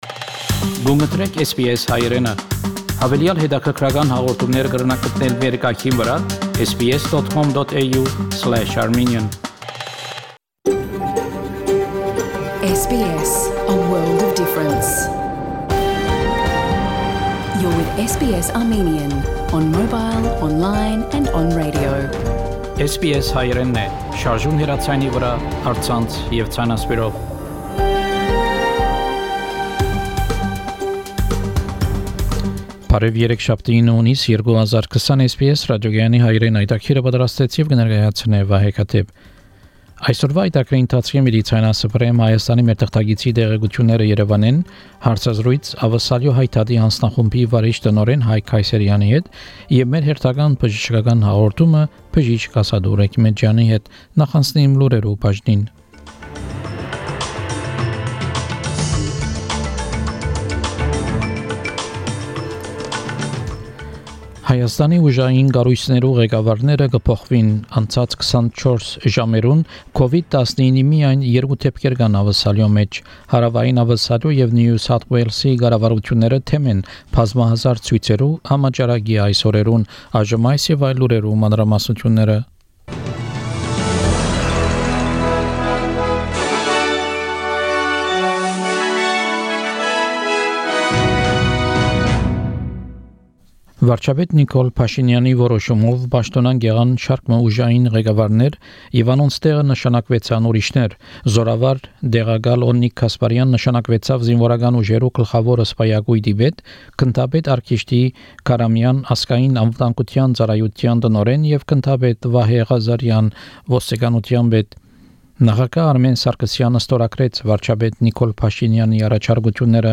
SBS Armenian news bulletin – 9 June 2020
SBS Armenian news bulletin from 9 June 2020 program.